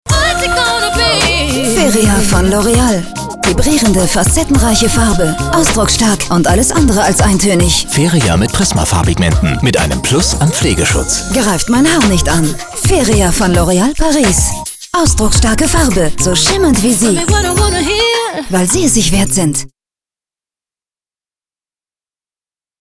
Sprecherin - Sängerin